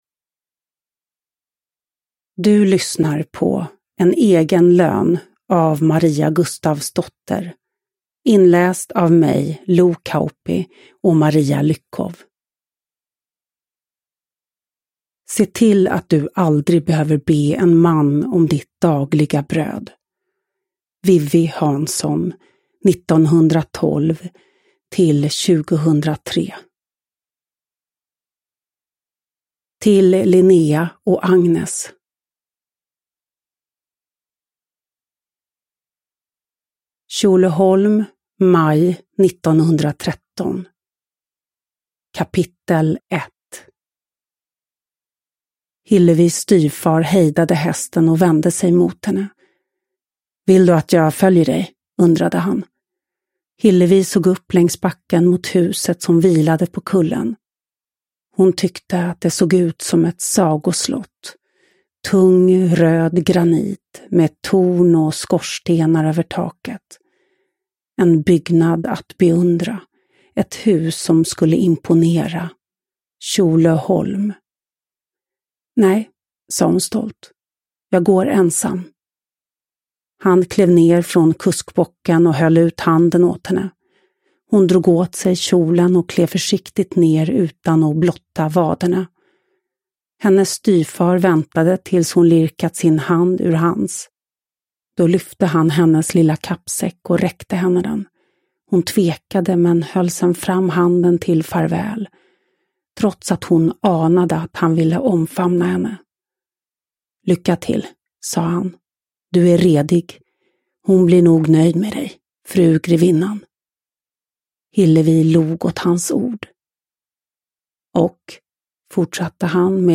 En egen lön (ljudbok) av Maria Gustavsdotter